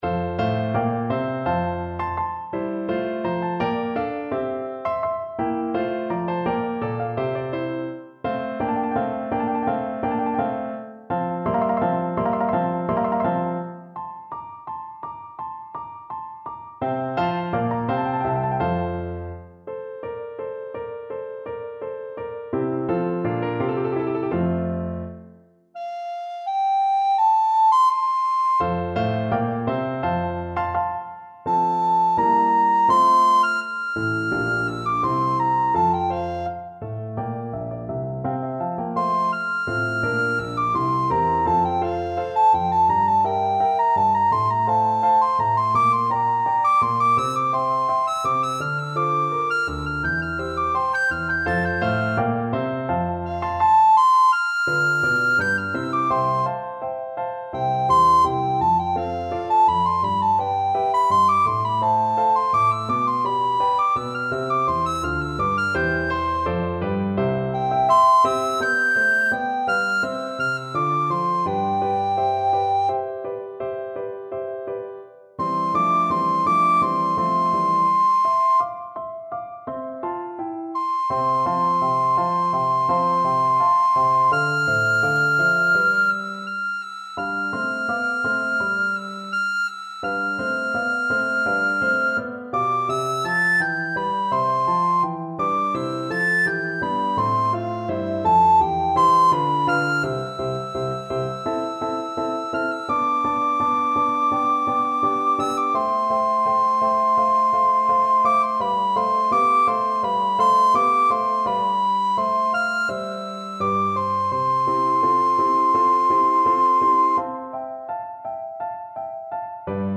Classical Handel, George Frideric Ev'ry valley shall be exalted from Messiah Soprano (Descant) Recorder version
4/4 (View more 4/4 Music)
F major (Sounding Pitch) (View more F major Music for Recorder )
Andante (=c.84)
Classical (View more Classical Recorder Music)